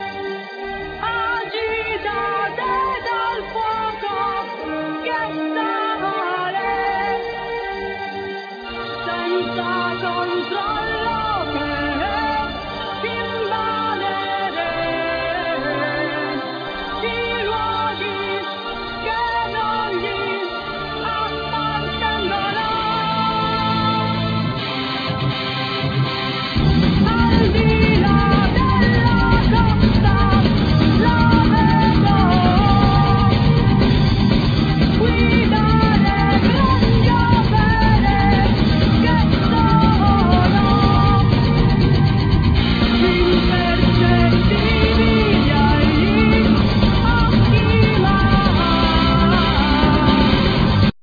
Keyboard,Orchestrations
Vocals
Drum,Programming